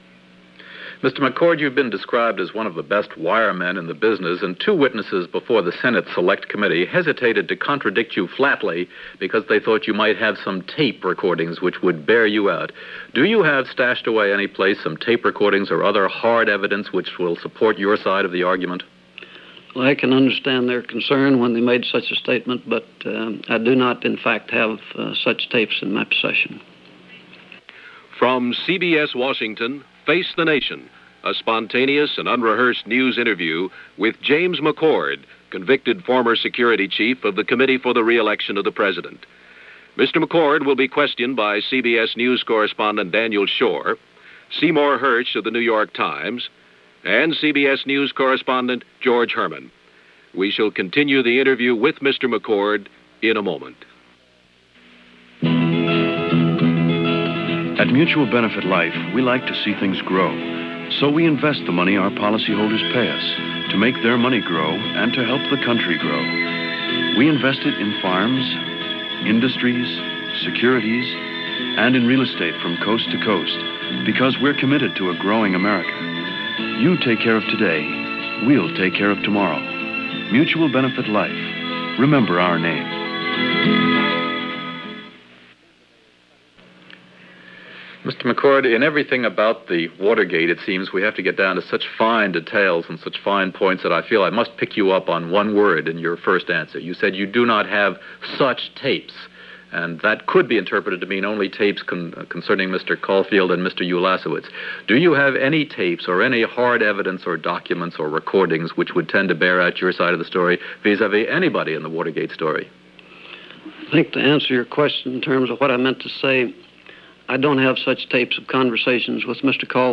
James McCord talks about wiretapping and Watergate - Face The Nation interview from June 3, 1973.